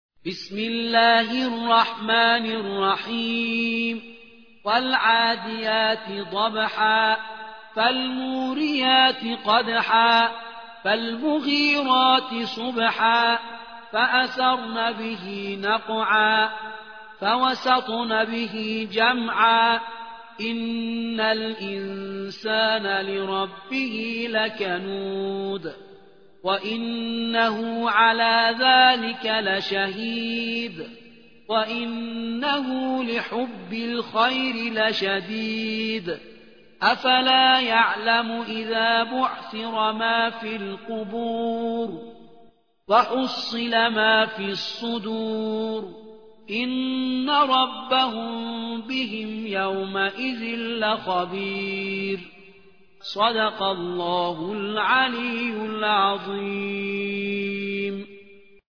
100. سورة العاديات / القارئ